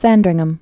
(săndrĭng-əm)